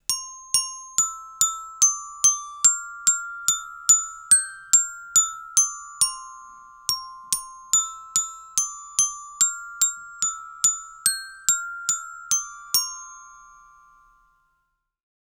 Melodía sencilla interpretada con un carillón
idiófono
percusión
campanilla
carillón